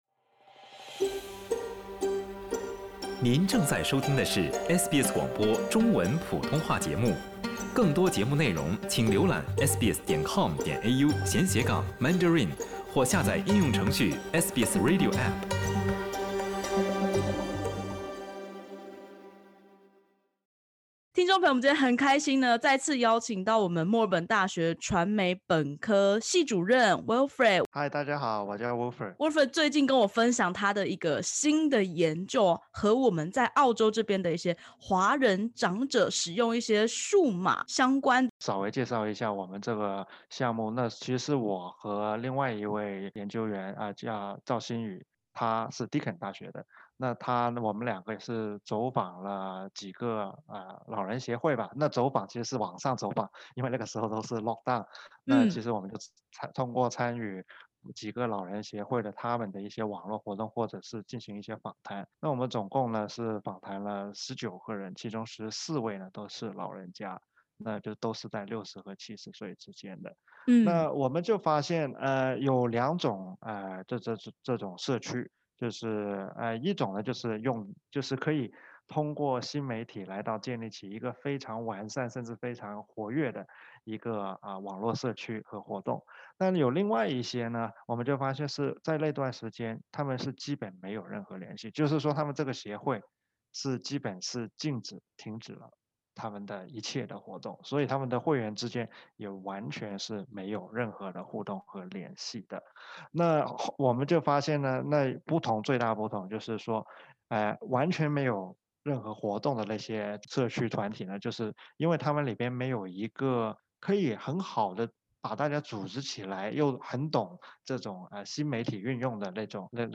根据一份由传媒学者所作的调研，COVID-19疫情大流行期间，各地澳华老人会对数字媒体和科技使用的熟悉度，有着极大的落差。点击首图收听采访音频。